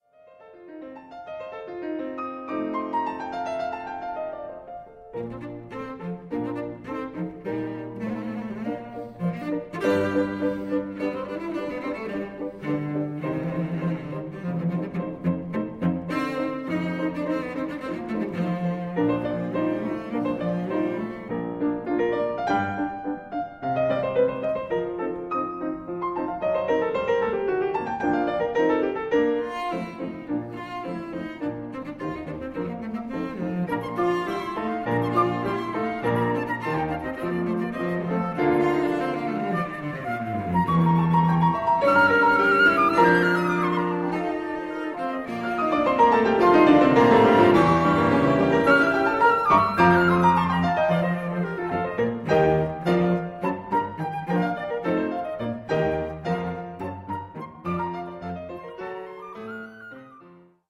Finale. Allegro